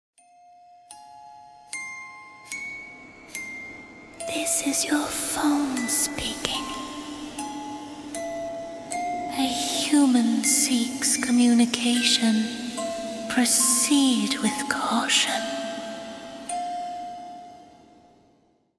Halloween Scary Horror Haunted Whisper Ghost